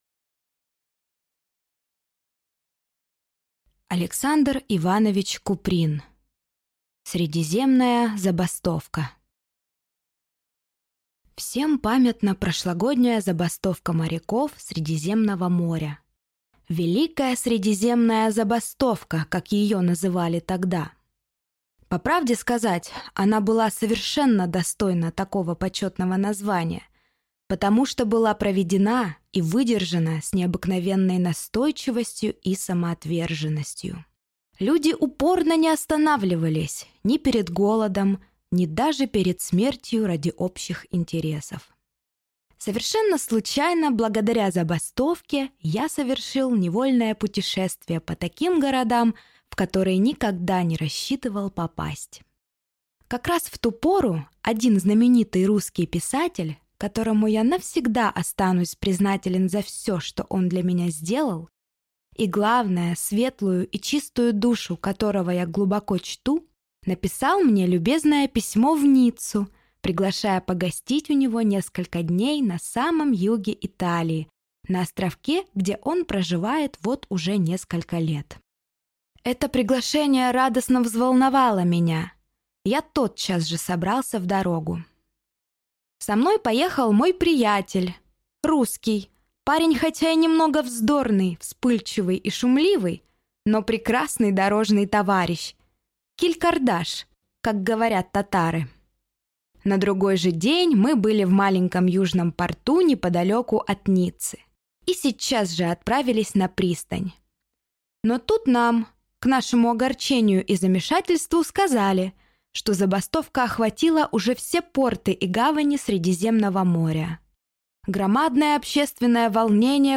Aудиокнига Средиземная забастовка